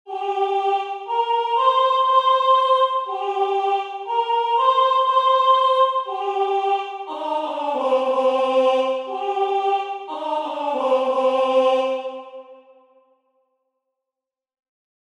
The Eskimos entertained Dr. Kane and his companions with a choral performance, singing their rude, monotonous song of “Amna Ayah” till the unfortunate white men were almost maddened by the discord. They improvised, moreover, a special chant in their honour, which they repeated with great gravity of utterance, invariably concluding with the sonorous and complimentary refrain of “Nalegak! nalegak! nalegak-soak!”—“Captain! captain! great captain!”